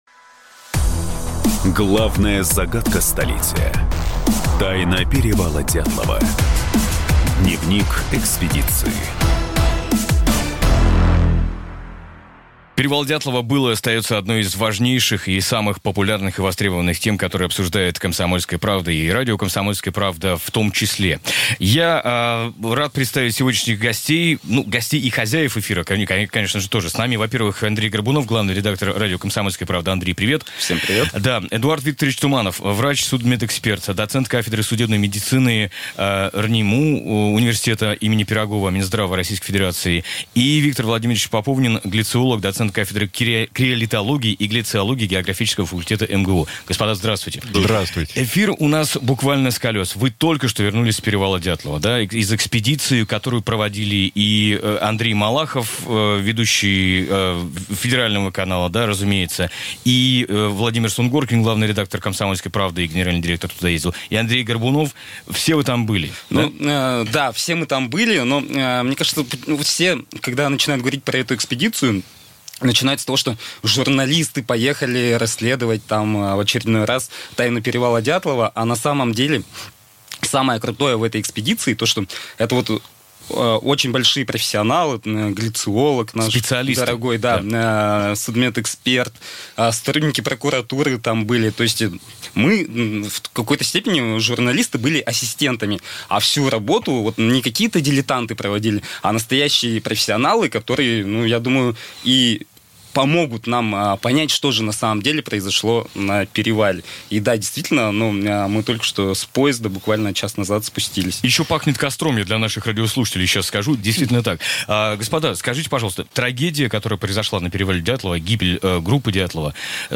Аудиокнига Первые выводы экспедиции на перевал Дятлова: кто и зачем убил туристов в 1959 году?